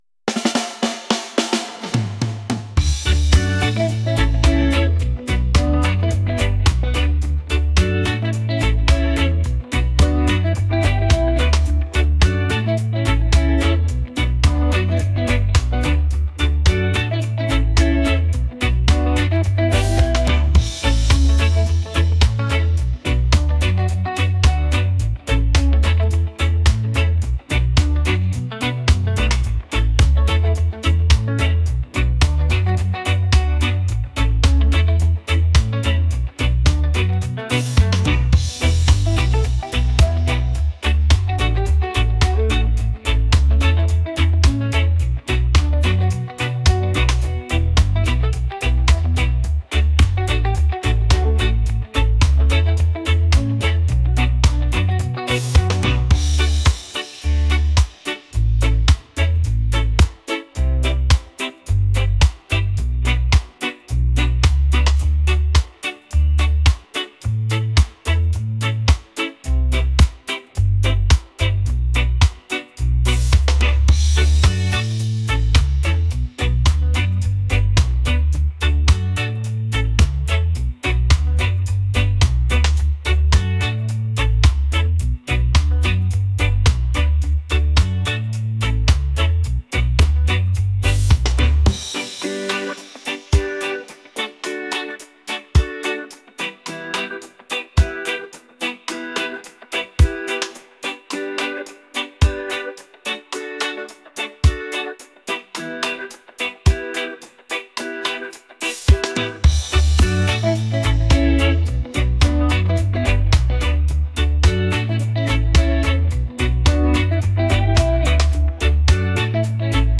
reggae | catchy | upbeat